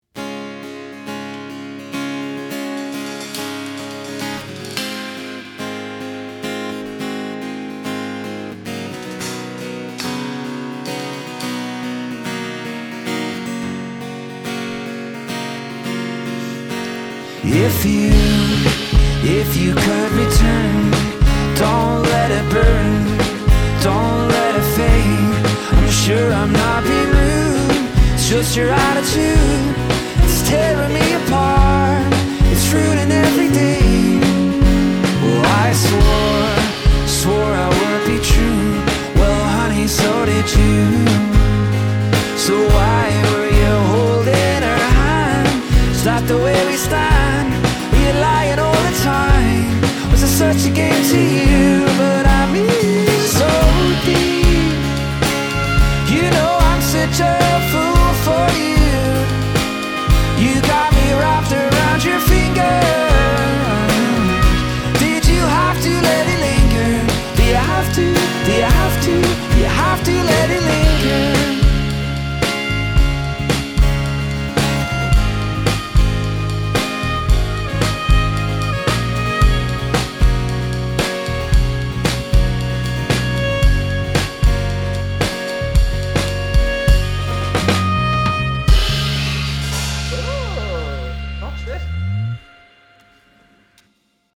thrilling upbeat folk outfit
folk pop outfit
fiddle